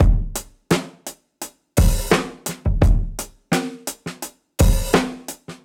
Index of /musicradar/dusty-funk-samples/Beats/85bpm
DF_BeatB_85-04.wav